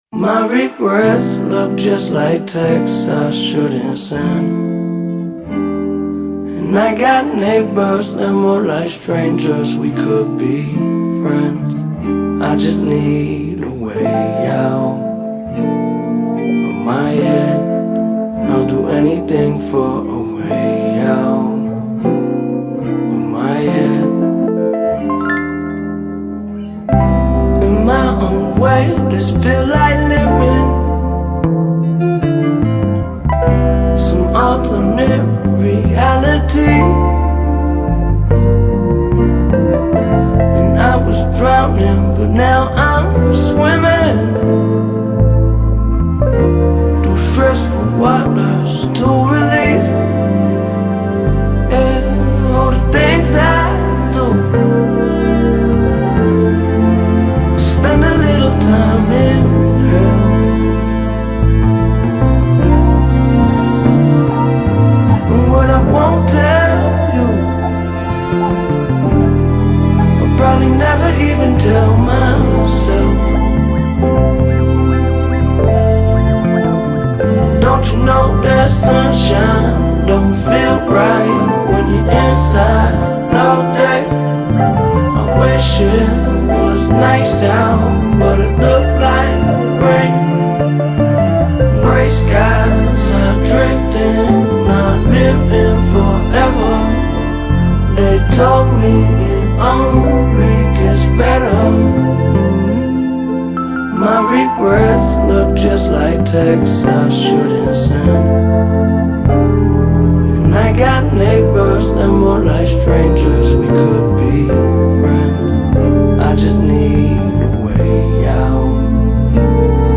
Hip Hop, Soul, Jazz Rap